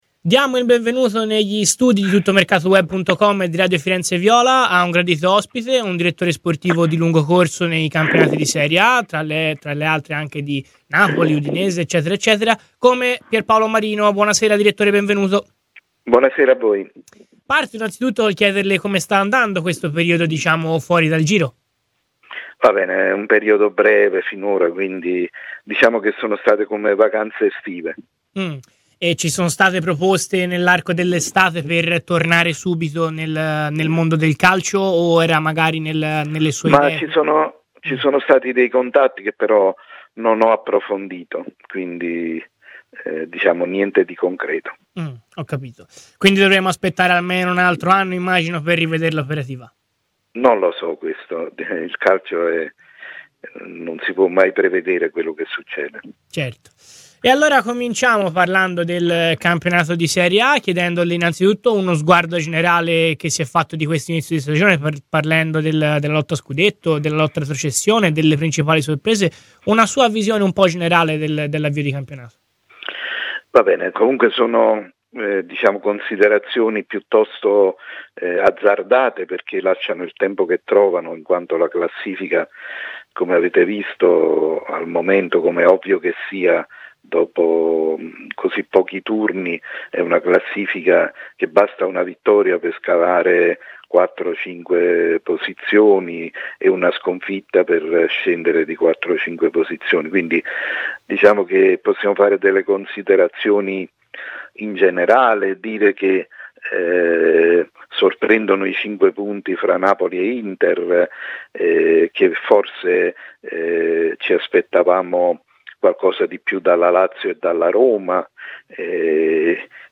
Tutti temi che in esclusiva a Radio FirenzeViola ha trattato Pierpaolo Marino , ex direttore sportivo dei friulani e dei partenopei.